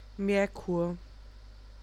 Ääntäminen
Ääntäminen Tuntematon aksentti: IPA: /ˈkvɪkˌsɪlvɛr/ Haettu sana löytyi näillä lähdekielillä: ruotsi Käännös Ääninäyte Substantiivit 1.